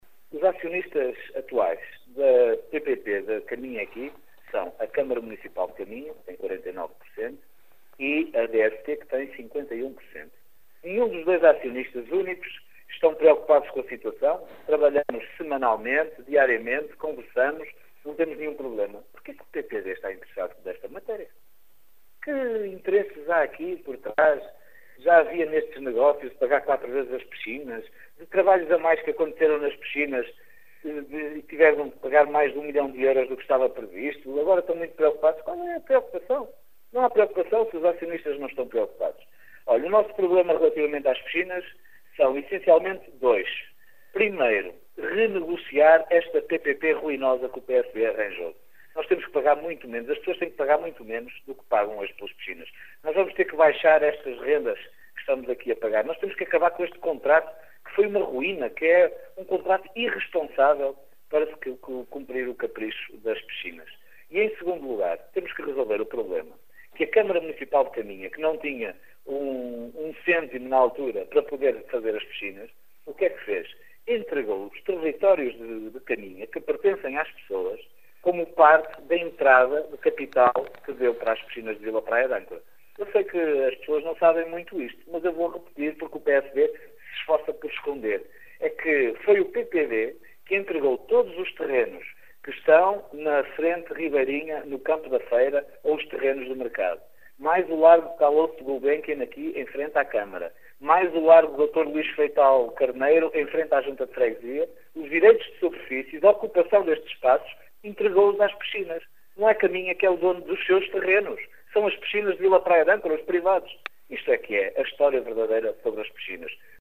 Em declarações à Rádio Caminha, Miguel Alves diz não entender as críticas da oposição em relação a uma obra “completamente ruinosa” para o concelho que, no final, vai custar 19 milhões de euros, ou seja, quatro vezes mais do que o valor inicial.